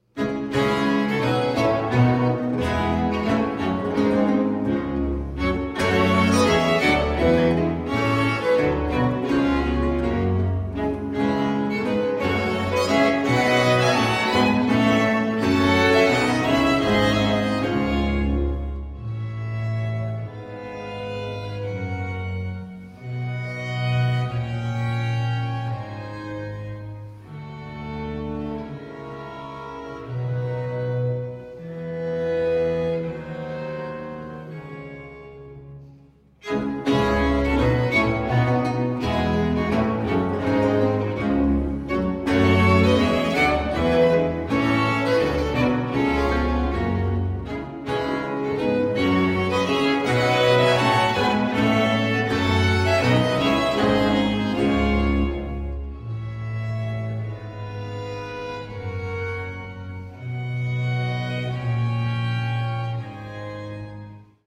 • Genres: Baroque, Renaissance
Sonata a4 in d
violin and viola
viola da gamba
violoncello
theorbo and guitar
organ and harpsichord
Recorded at the Old Meeting House, Francestown, NH
Baroque string ensemble ACRONYM is dedicated to giving modern premieres of the wild instrumental music of the seventeenth century.